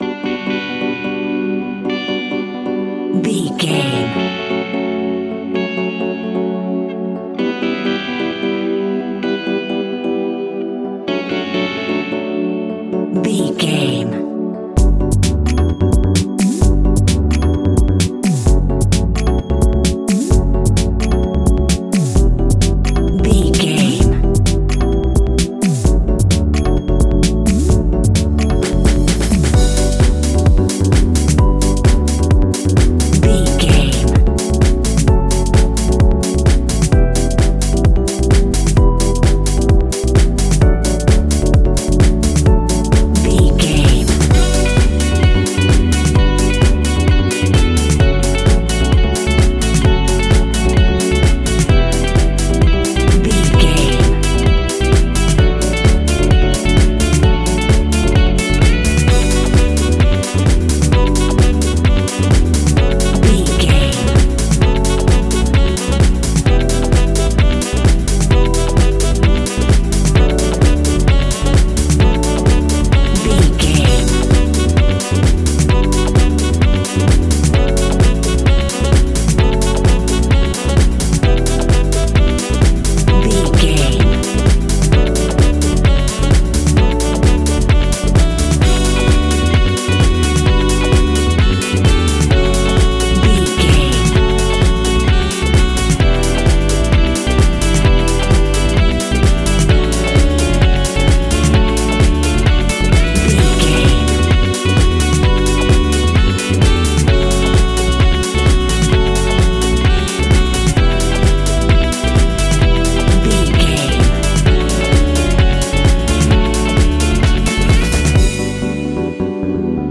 Aeolian/Minor
uplifting
energetic
synthesiser
electric piano
bass guitar
saxophone
drum machine
groovy
instrumentals